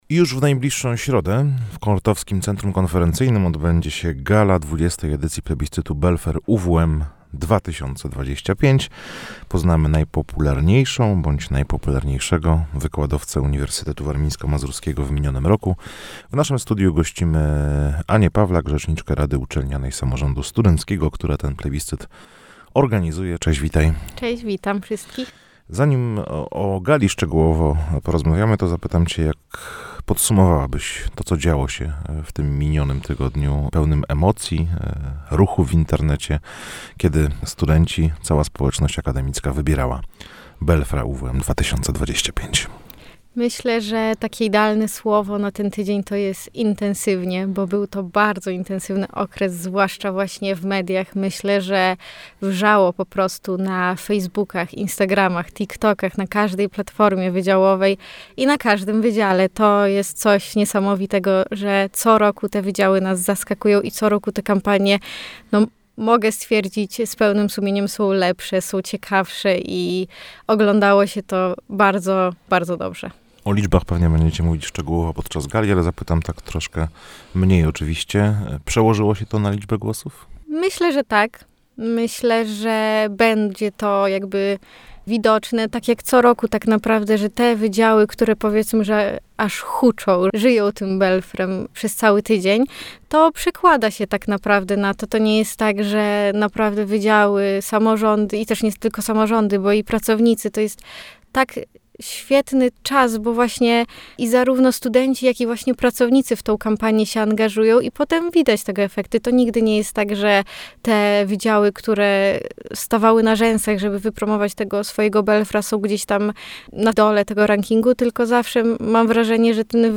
– mówiła w naszym studiu